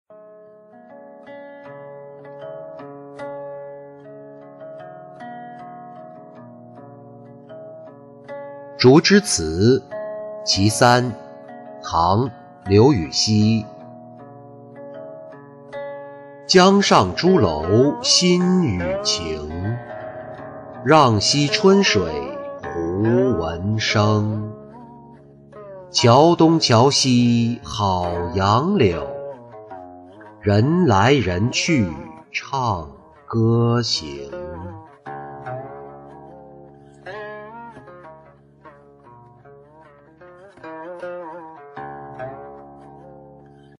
竹枝词九首·其三-音频朗读